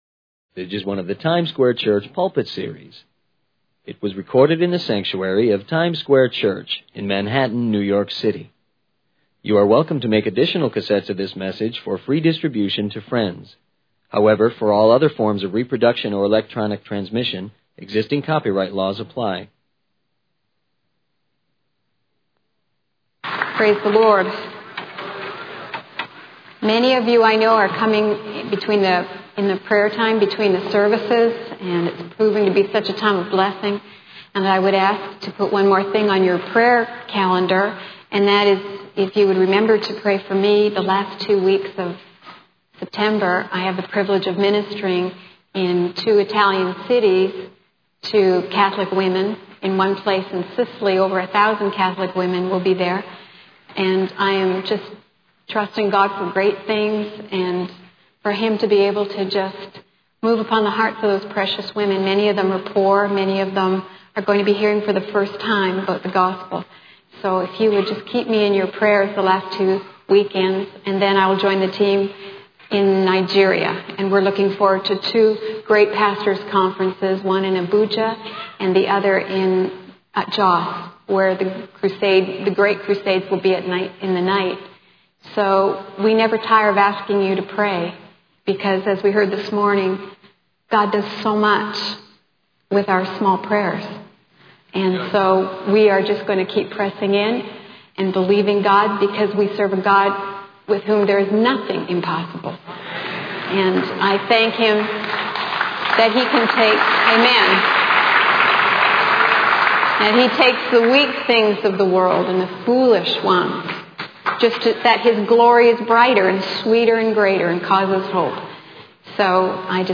The sermon is titled 'Lessons from the Pit' and the speaker begins by praying for guidance and understanding from the Holy Spirit. The message focuses on the story of Joseph from the Bible, specifically the part where his brothers plot to kill him but instead throw him into a pit.
It was recorded in the sanctuary of Times Square Church in Manhattan, New York City.